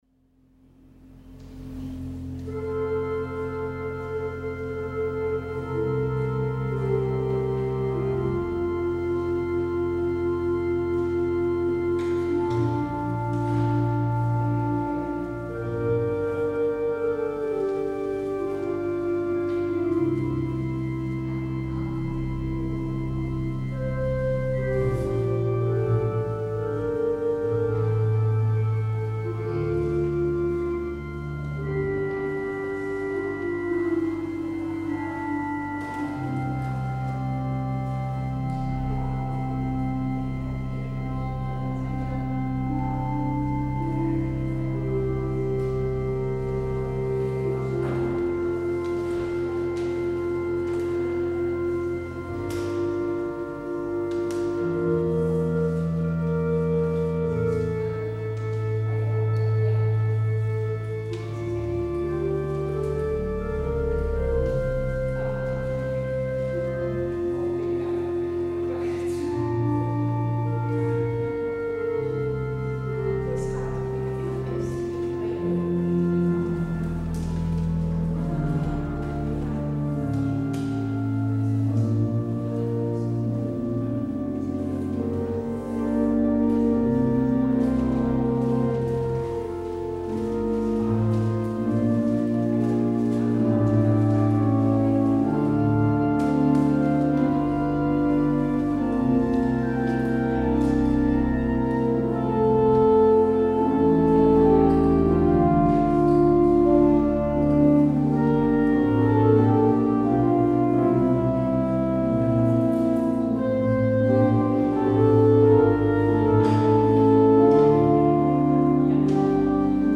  Luister deze kerkdienst hier terug: Alle-Dag-Kerk 23 mei 2023 Alle-Dag-Kerk https
Het openingslied is Lied 637, vs. 1, 2, 3 en 4.